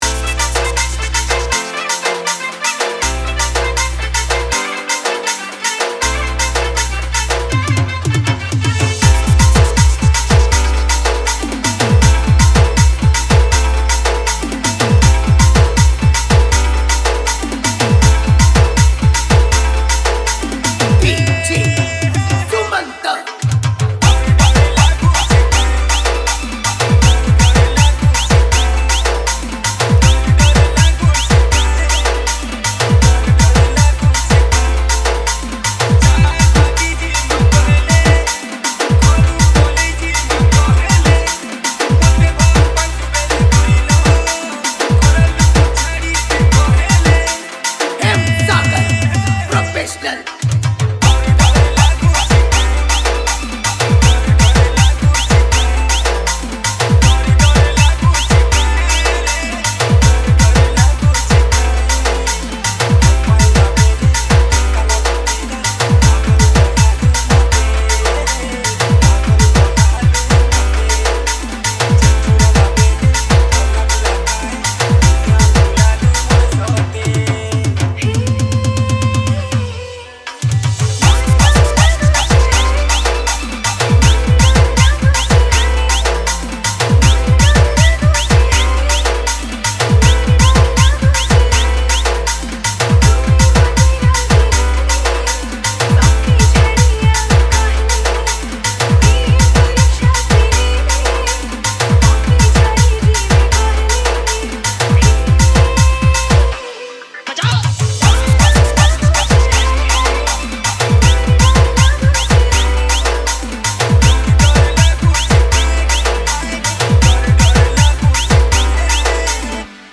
• Category: New Sambalpuri DJ Song 2025